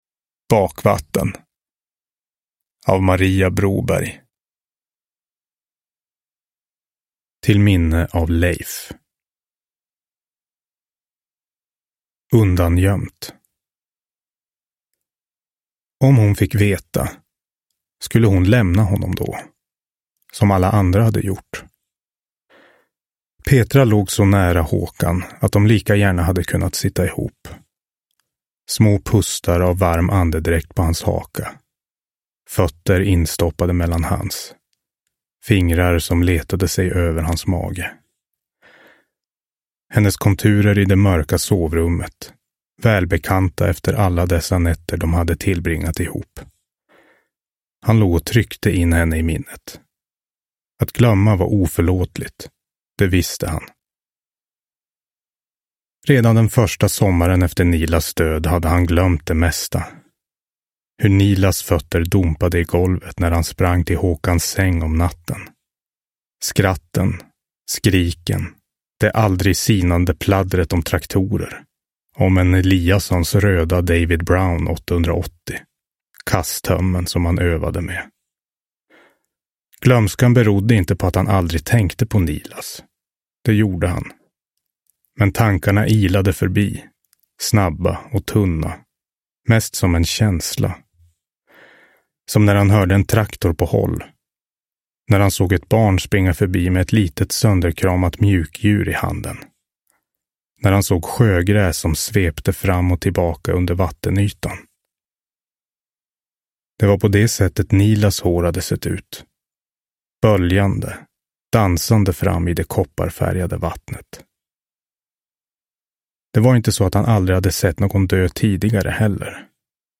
Bakvatten – Ljudbok – Laddas ner